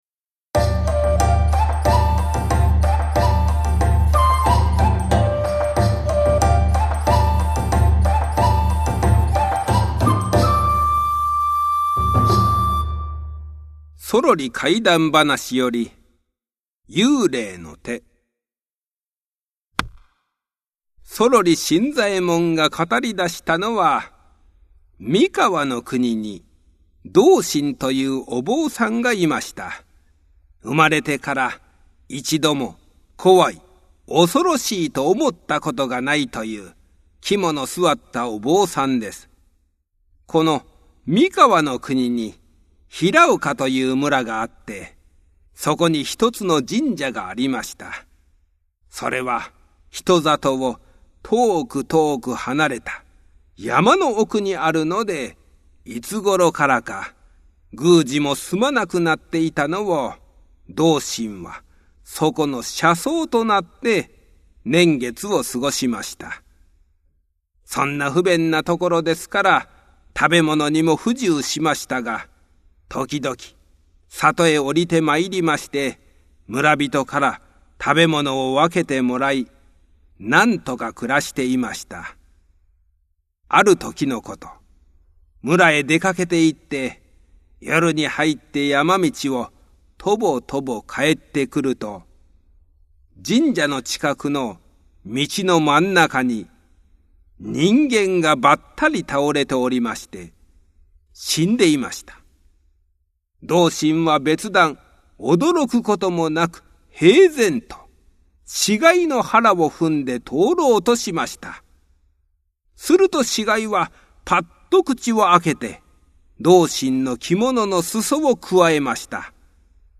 お子様向け短めの講談を楽しんでください♪
近頃、注目を浴びているのが日本の伝統話芸「講談」。